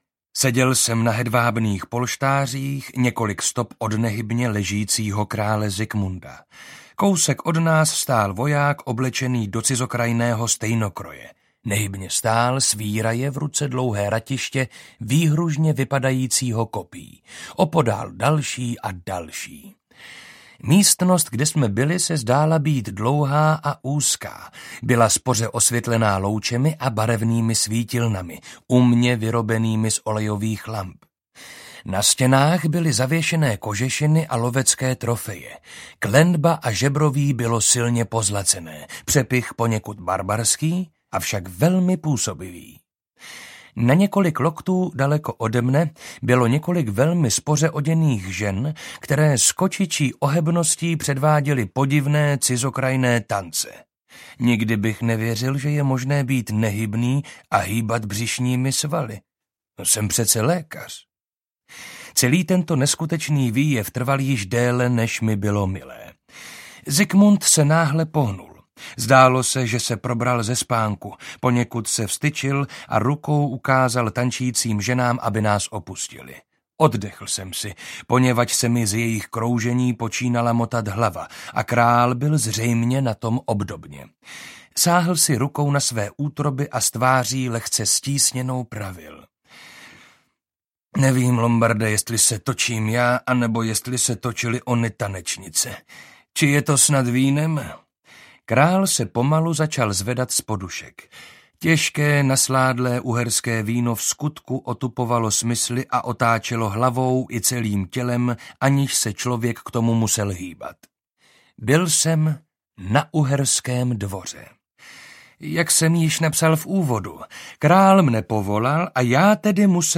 Zikmund Lucemburský audiokniha
Ukázka z knihy